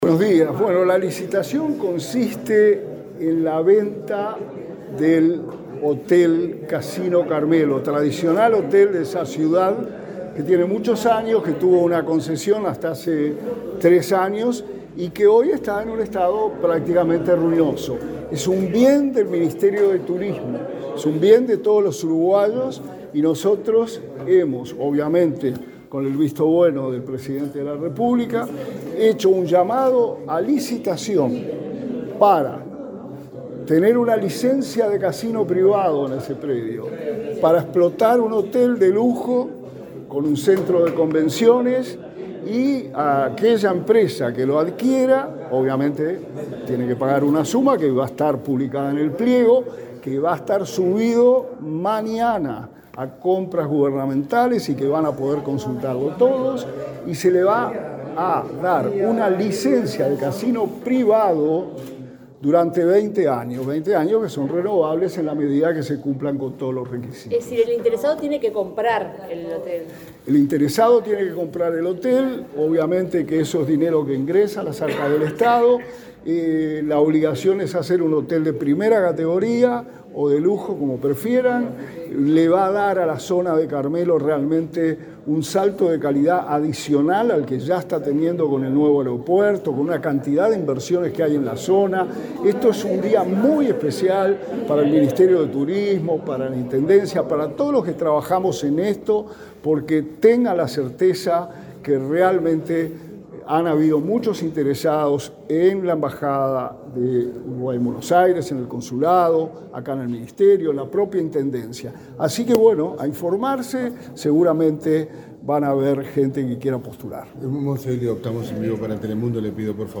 Declaraciones del subsecretario de Turismo, Remo Monzeglio
Este lunes 26 en Montevideo, el subsecretario de Turismo, Remo Monzeglio, dialogó con la prensa, luego de participar en el lanzamiento del llamado a